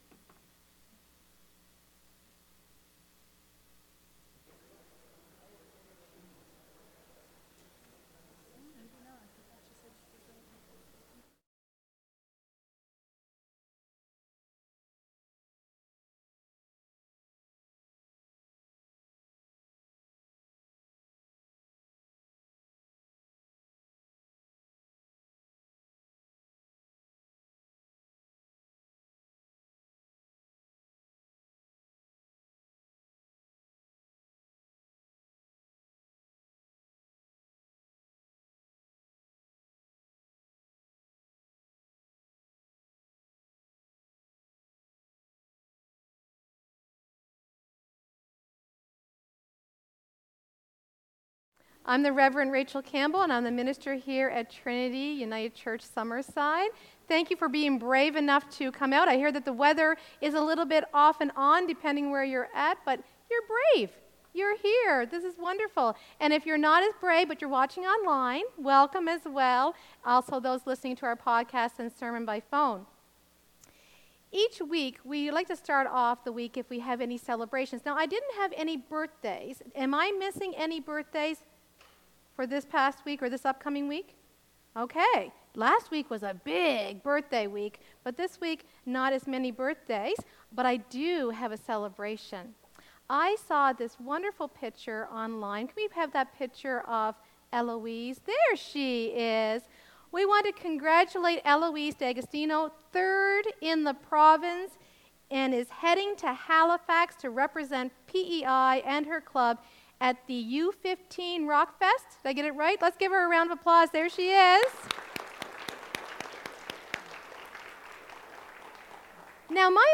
live-worship-service-march-9th-2025.mp3